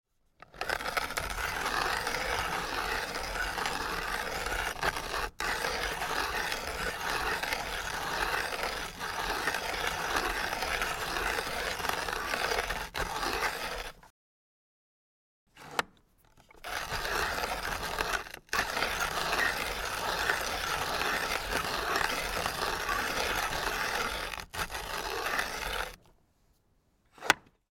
Lehnartz coffee grinder